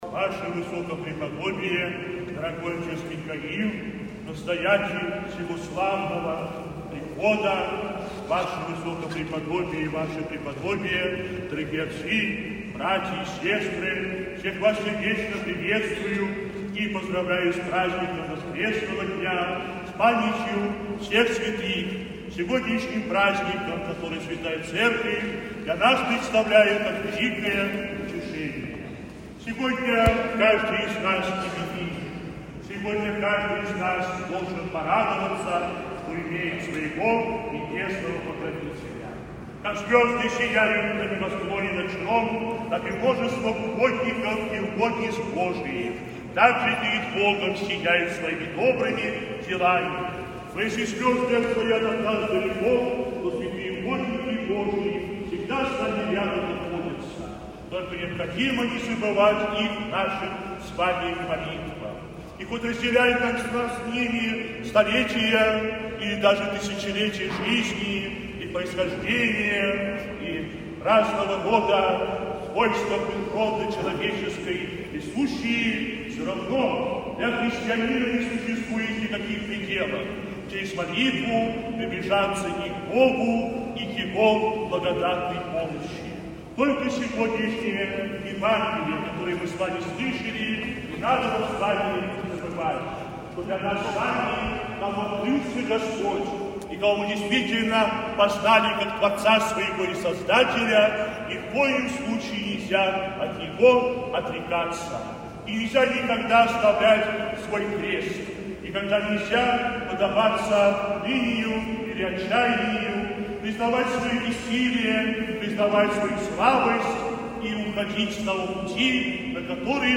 В храме молились выпускники школ Красносельского района, родители, директора, учителя, прихожане.
Напутственное-слово-епископа-Силуана.mp3